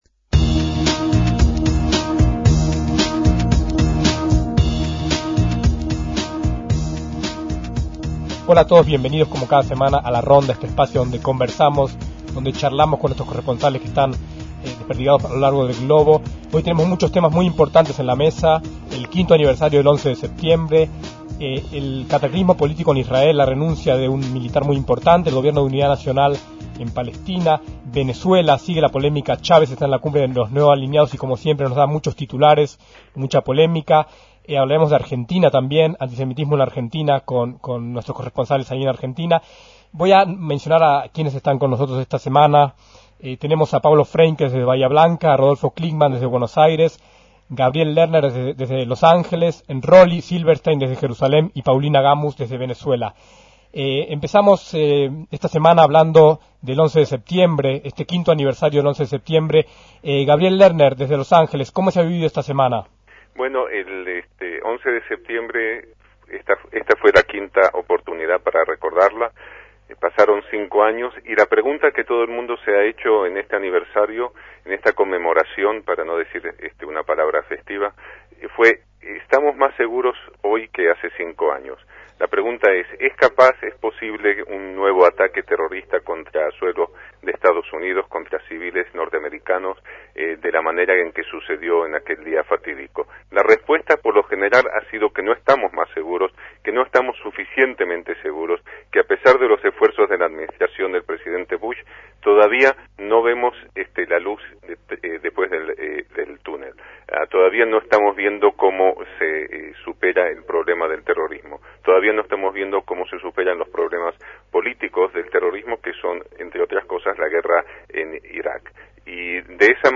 La ronda semanal de corresponsales